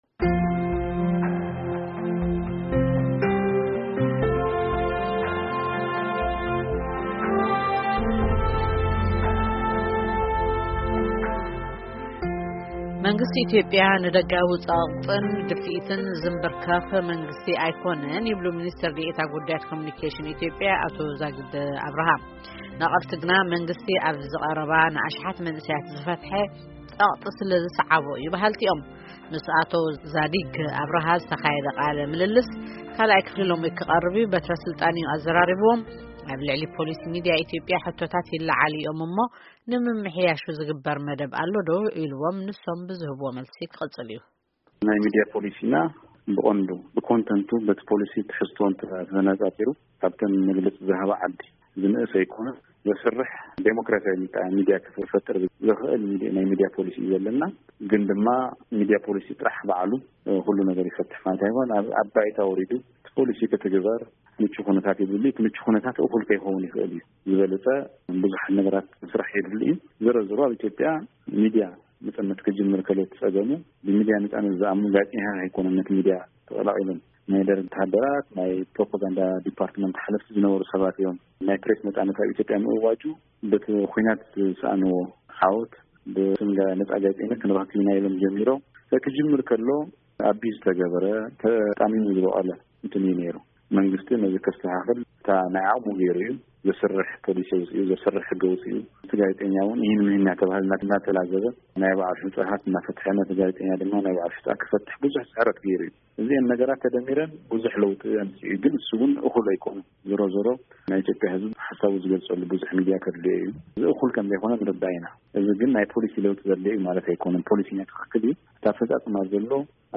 ቃለ ምልልስ ምስ ኣቶ ዛድግ ኣብራሃ (2ይ ክፋል)